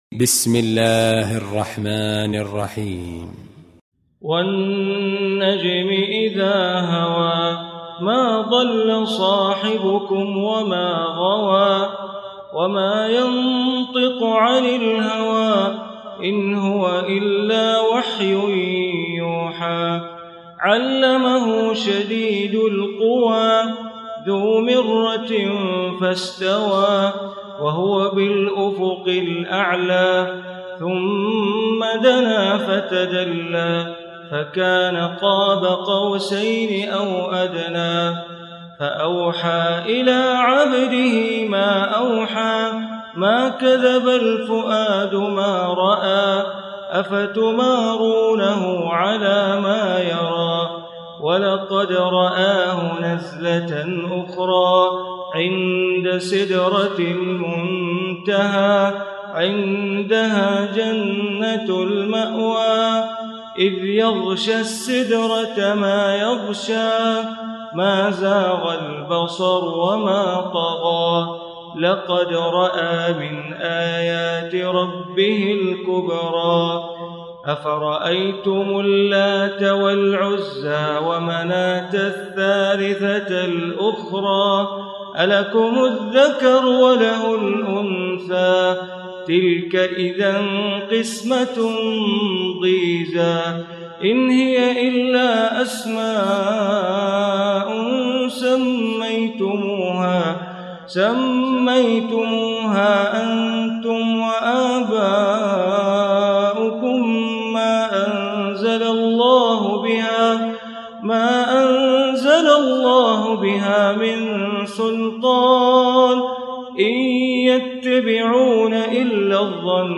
Surah An-Najm Recitation by Sheikh Bandar Baleela
Surah An-Najm, listen online mp3 tilawat / reciation in Arabic recited by Imam e Kaaba Sheikh Bandar Baleela.